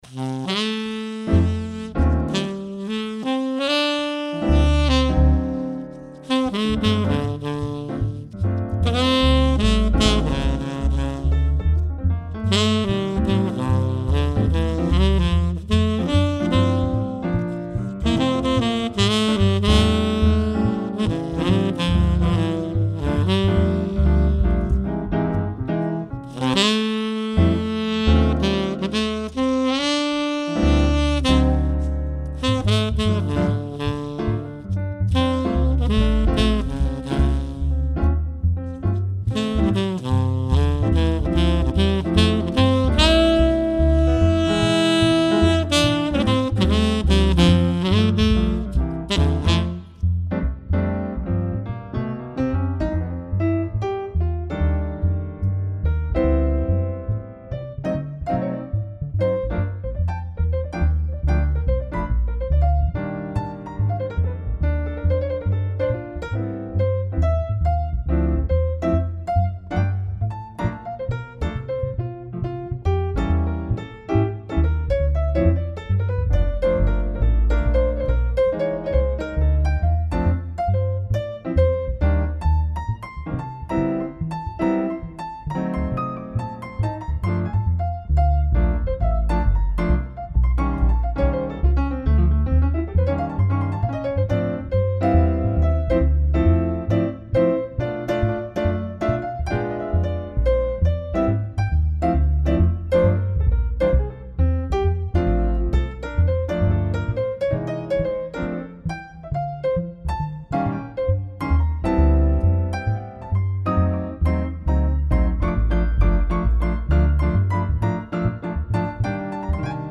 saxophone
piano
upright bass